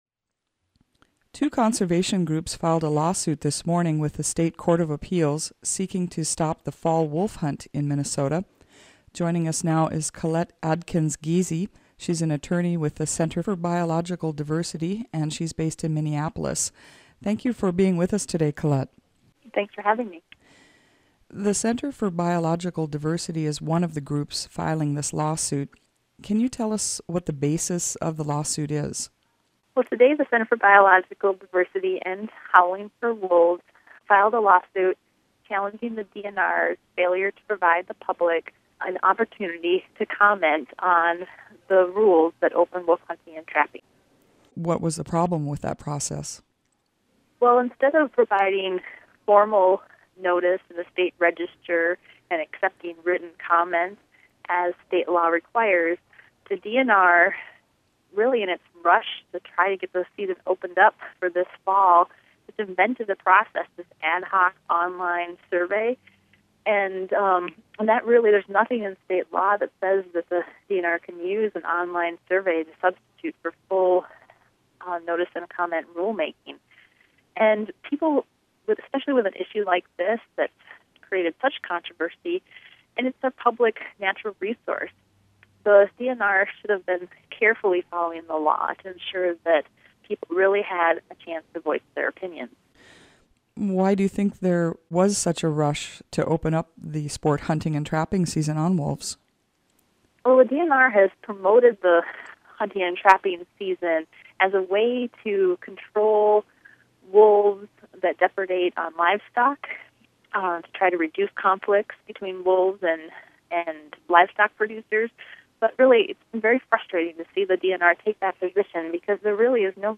(Click on the mp3 audio file above to hear an interview, recorded Tuesday, September 18,  with a representative of The Center for Biological Diversity.)